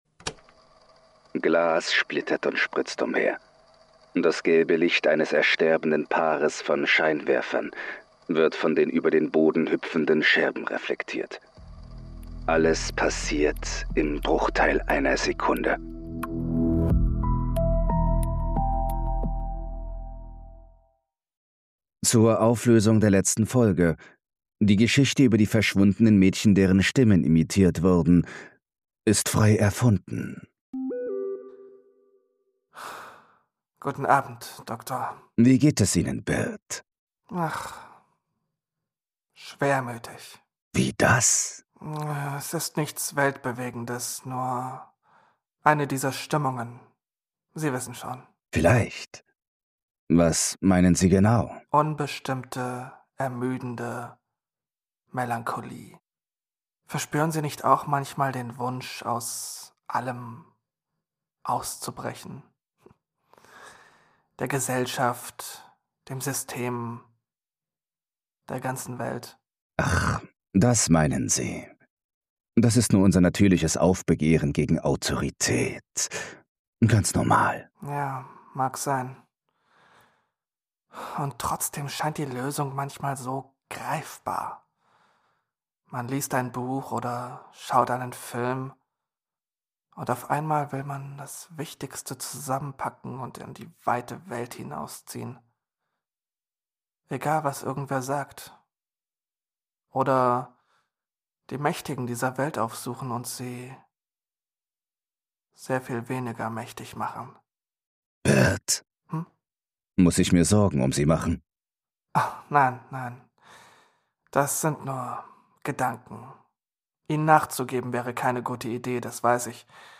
Ein deutscher Hörbuch-Podcast zwischen True Crime und Mystery – zum Miträtseln, Ablenken und Einschlafen.